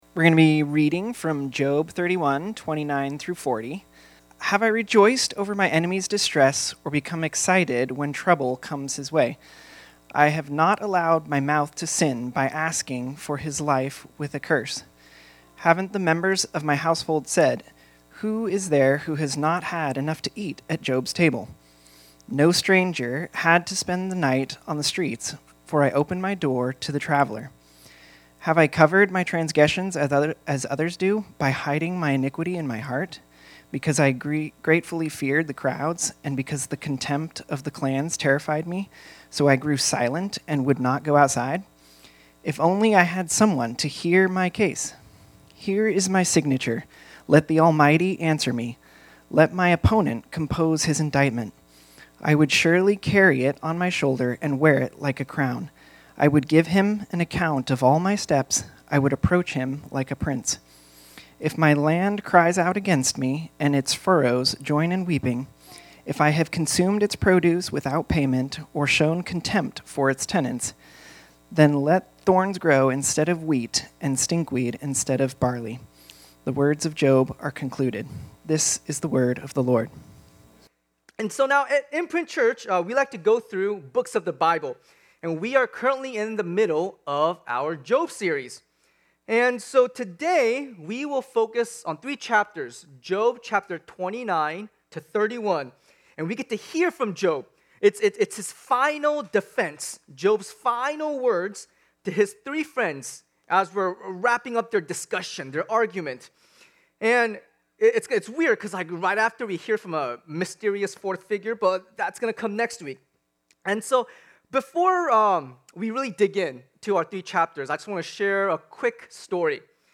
This sermon was originally preached on Sunday, February 22, 2026.